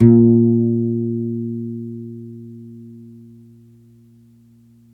GUITARON 03R.wav